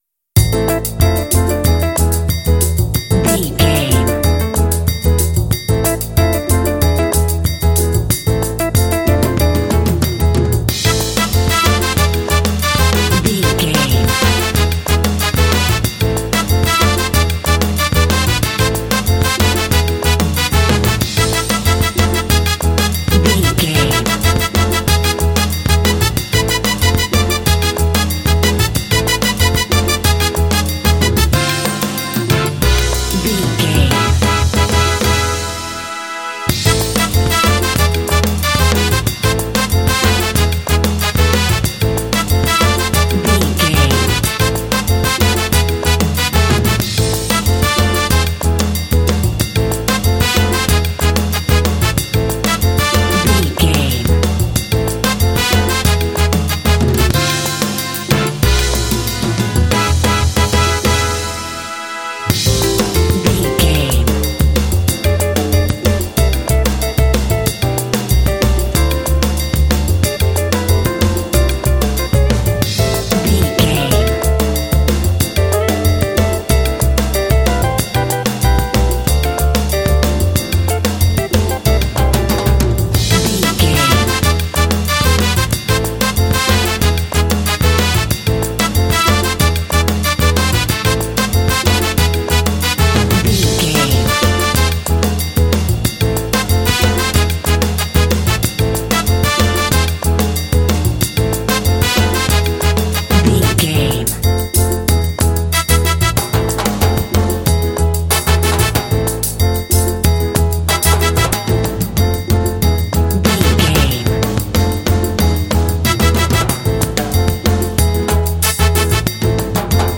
This smooth track is great for racing games
Aeolian/Minor
smooth
percussion
drums
bass guitar
electric guitar
trumpet
piano
Lounge
downtempo